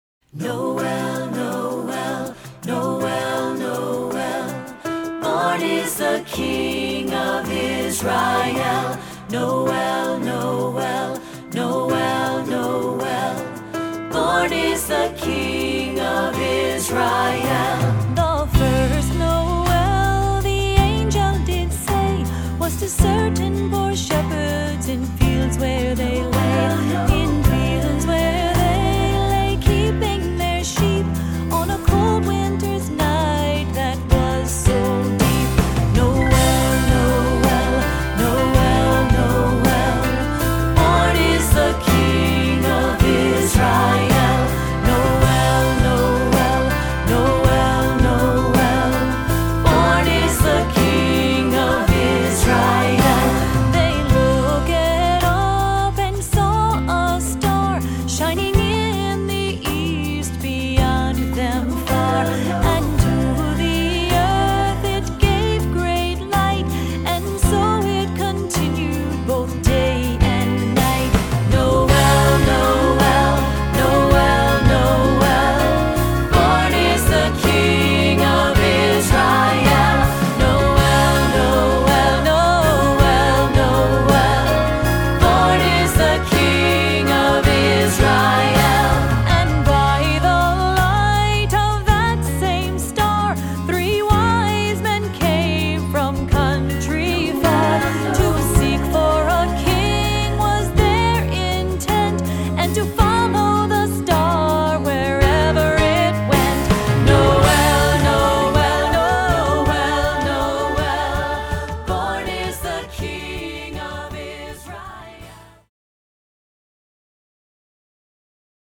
Voicing: SAATB; Solo; Assembly